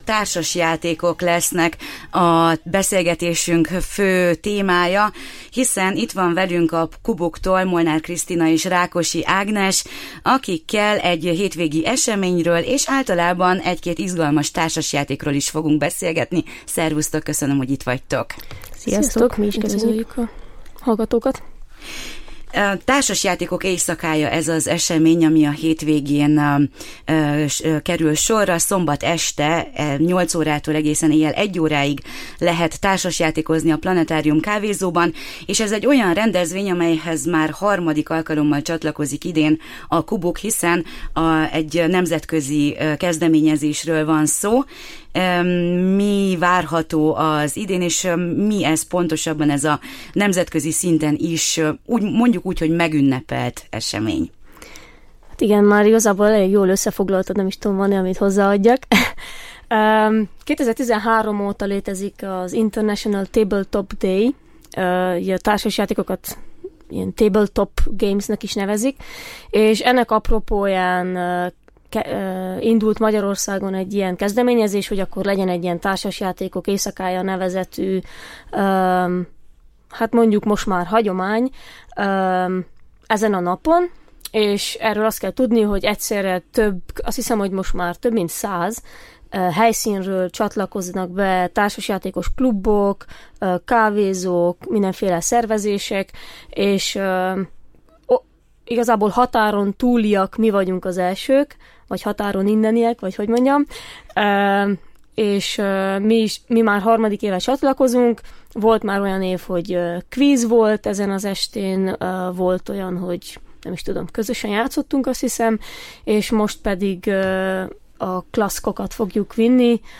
Szombaton ismét lesz Társasjátékok Éjszakája Kolozsváron. A Kubuk csapata mesélt róla, és nyári, utazós játékokat is ajánlanak.